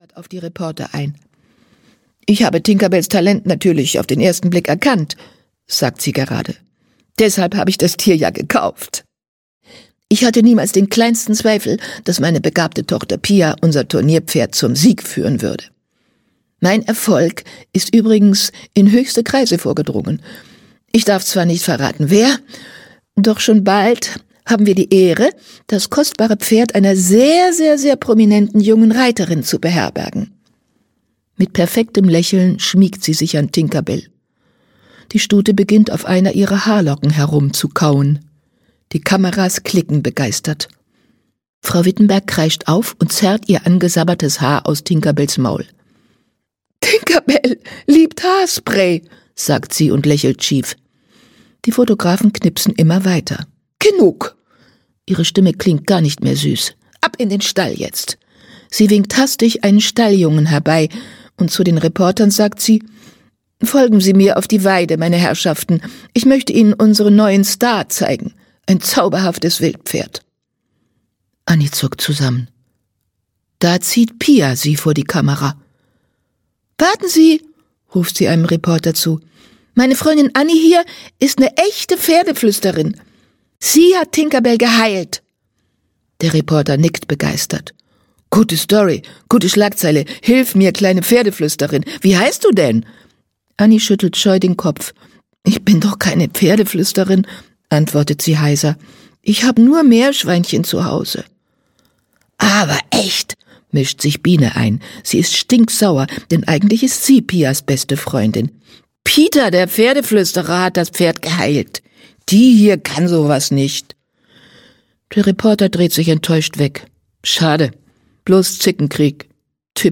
Ponyherz 4: Das Pferd der Prinzessin - Usch Luhn - Hörbuch